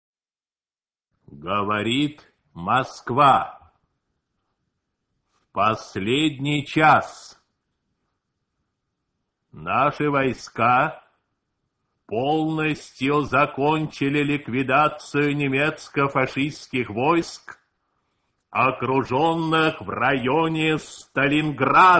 На этой странице собраны архивные записи голоса Юрия Левитана — символа эпохи.
Качество звука восстановлено, чтобы передать мощь и тембр легендарного диктора.